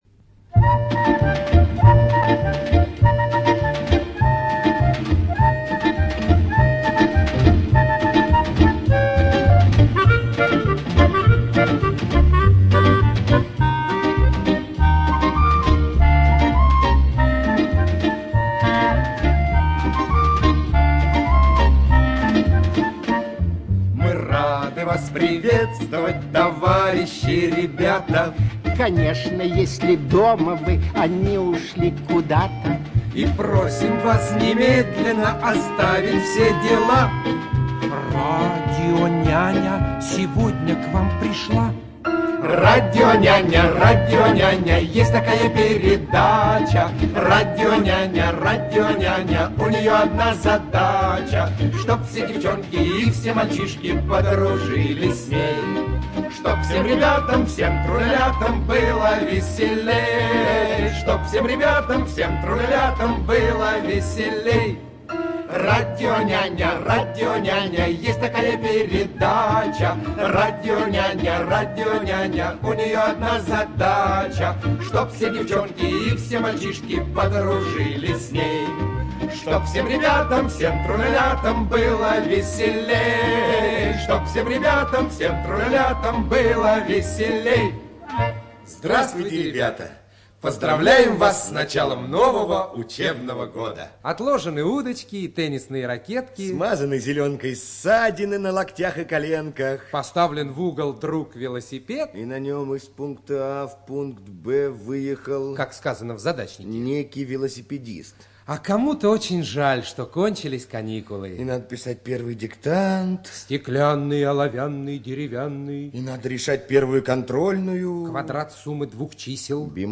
ЖанрДетская литература, Радиопрограммы